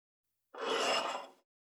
394,机の上をスライドさせる,スー,ツー,サッ,シュッ,スルッ,ズズッ,スッ,コト,トン,
効果音